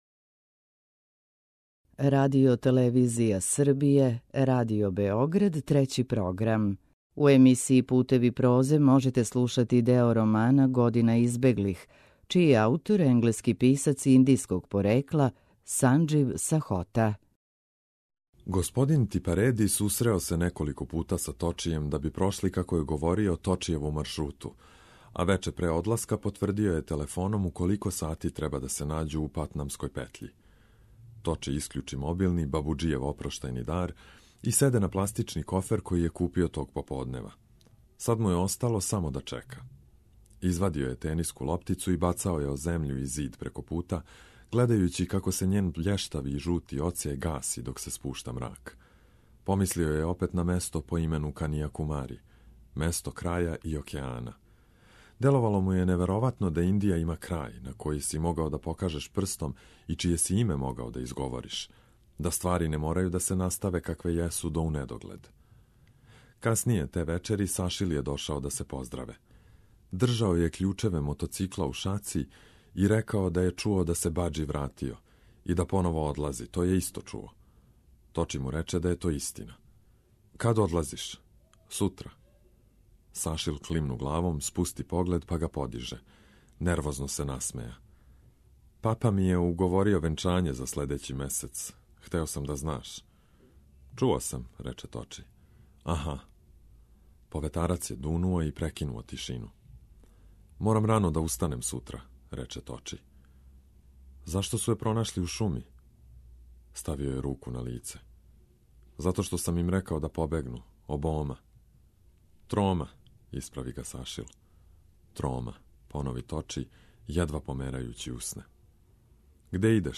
Књига за слушање: Санџив Сахота: Година избеглих (6/7)